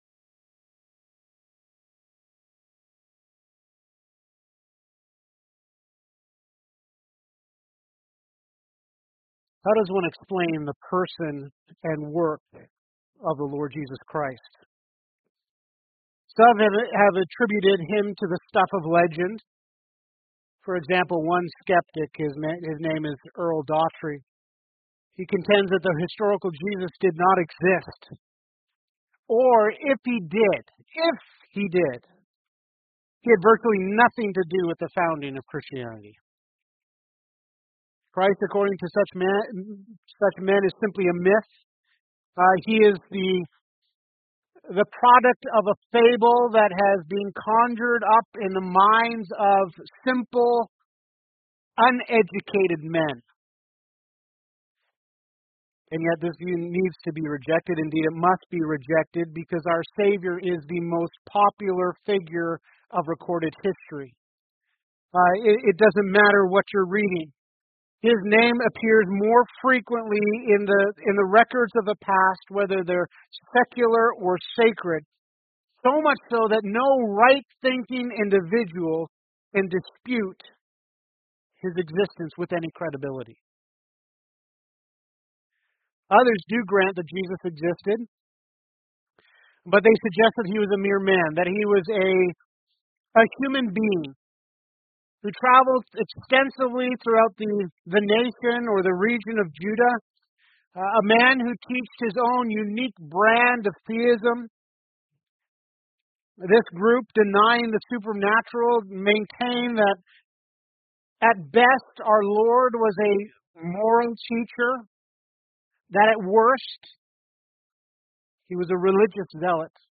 Sunday Sermon Series